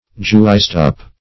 Search Result for " juiced-up" : The Collaborative International Dictionary of English v.0.48: juiced-up \juiced"-up`\ (j[=u]st"-[u^]p`), a. 1.